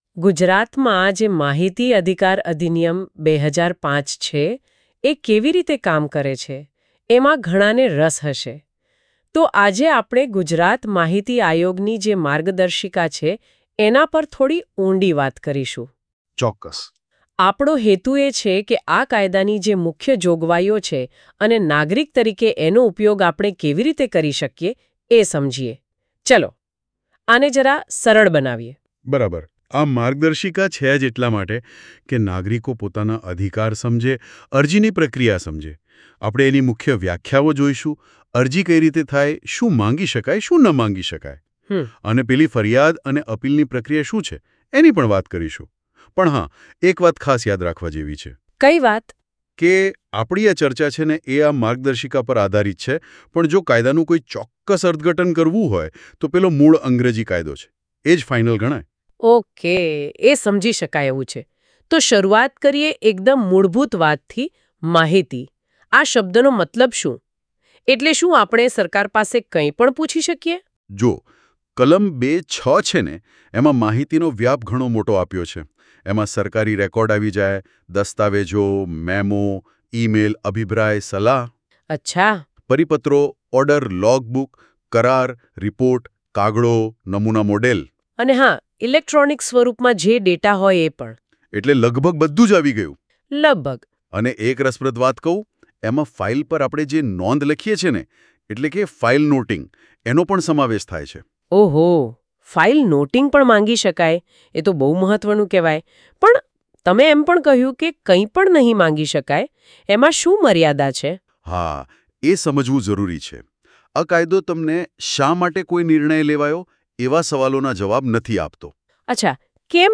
અરજદારો માટે (7.0 MB) માહિતી અધિકાર અધિનિયમ અંતર્ગત તૈયાર કરવામાં આવેલ લઘુપુસ્તિકા ની સુધારેલ આવૃત્તિ સંદર્ભે આર્ટિફિશિયલ ઇન્ટેલિજન્સ (AI) સોફ્ટવેર ના ઉપયોગથી તૈયાર કરવામાં આવેલ પોડકાસ્ટ જાહેર માહિતી અધિકારીઓ માટે (30.3 MB) પ્રથમ અપીલ અધિકારીઓ માટે (20.3 MB) આર.